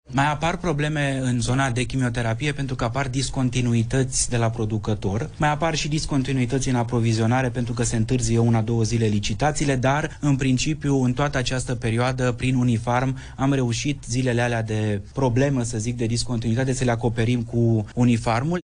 Ministrul demisionar Alexandru Rogobete: „Mai apar probleme în zona de chimioterapie”